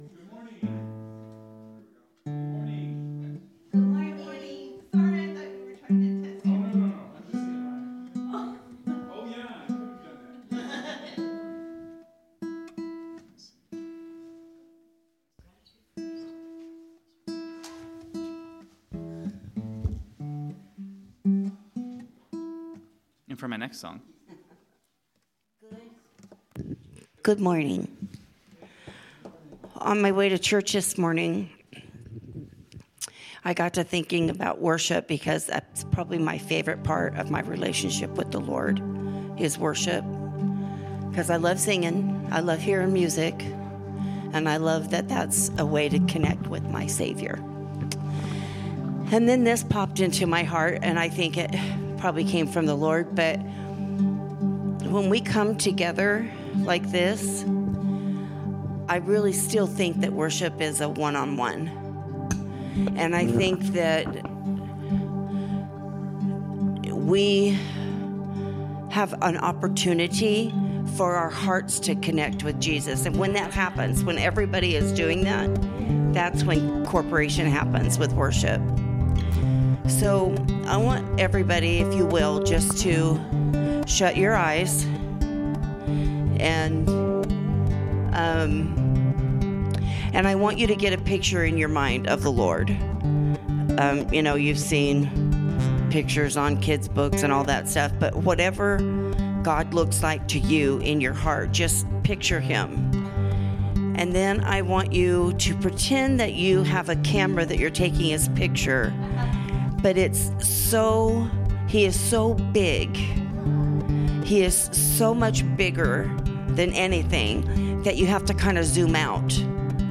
From Series: "Sermon"